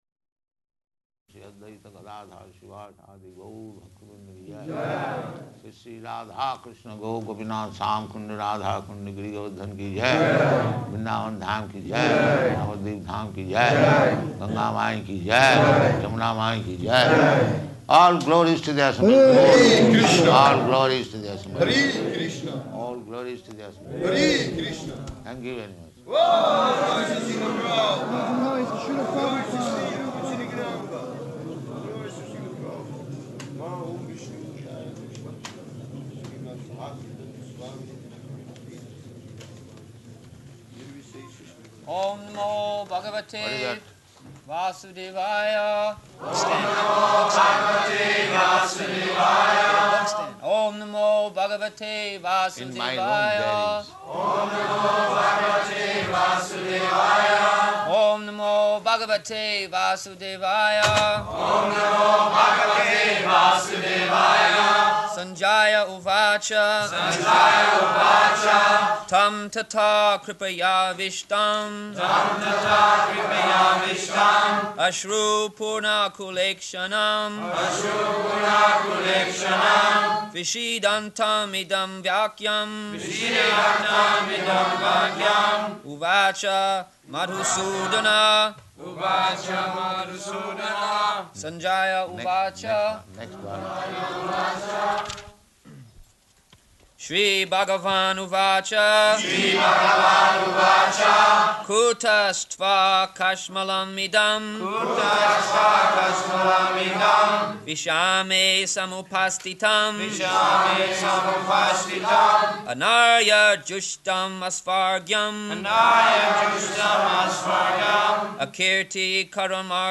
June 16th 1974 Location: Germany Audio file
[devotees repeat] Prabhupāda: [aside:] What is that?